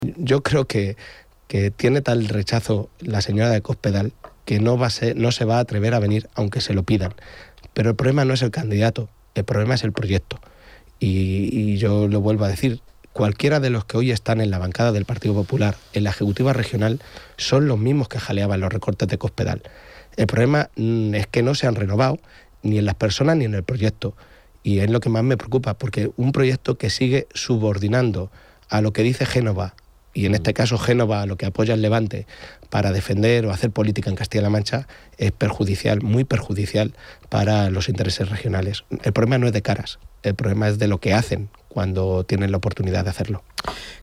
En una entrevista en Onda Cero Castilla-La Mancha
Cortes de audio de la rueda de prensa